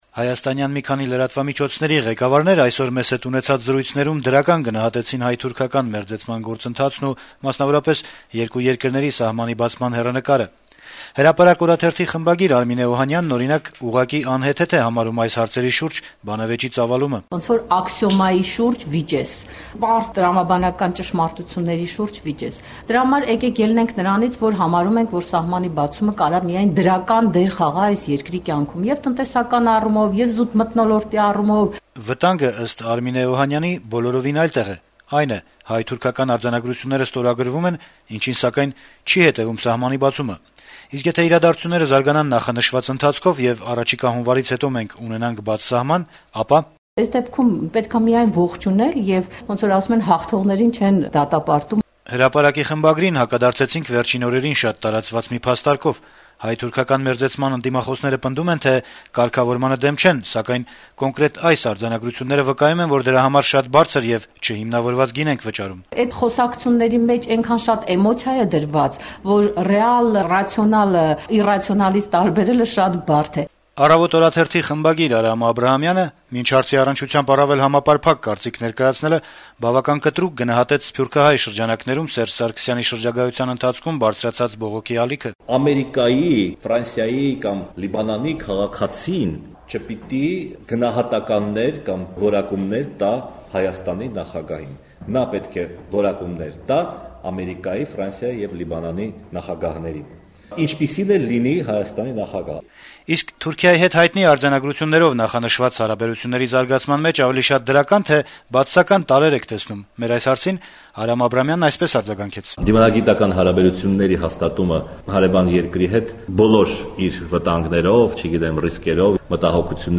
Հայաստանյան մի քանի լրատվամիջոցների ղեկավարներ երեքշաբթի օրը «Ազատություն» ռադիոկայանի հետ զրույցներում դրական գնահատեցին հայ-թուրքական մերձեցման գործընթացն ու, մասնավորապես, երկու երկրների սահմանի բացման հեռանկարը։